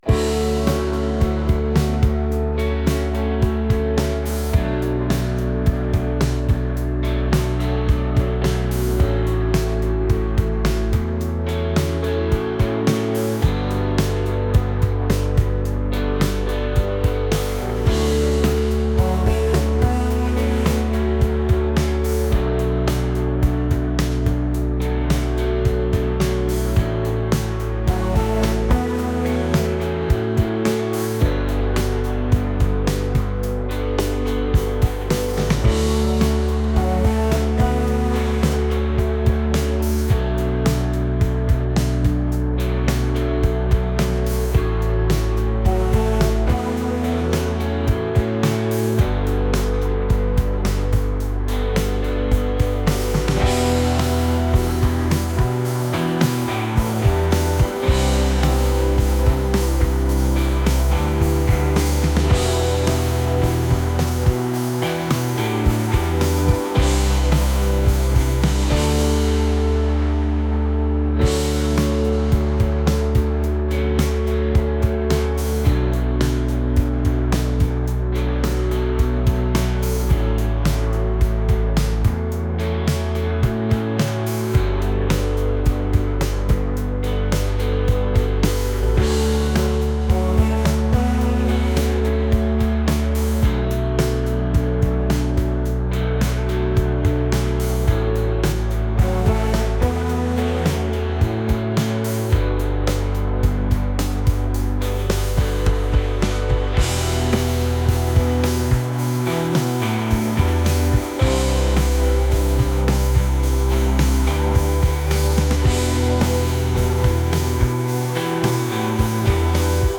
indie | acoustic | folk